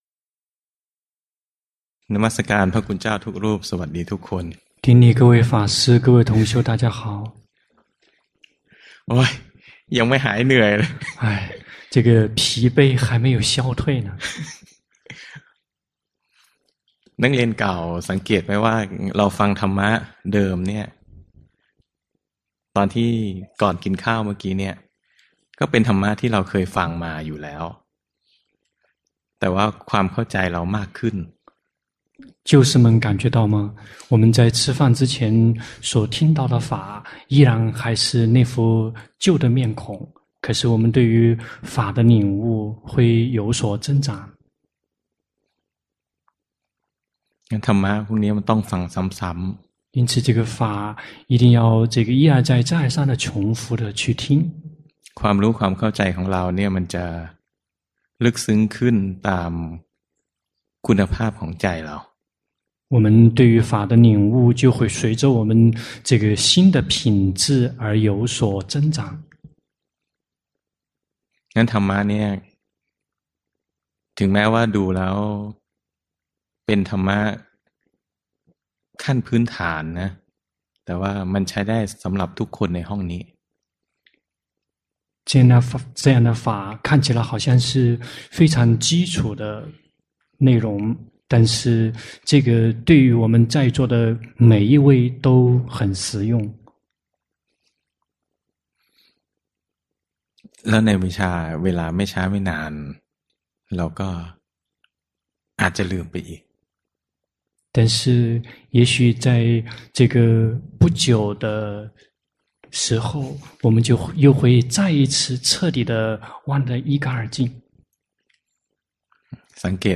長篇法談｜如果心對了，開發智慧則無需耗時太久 - 靜慮林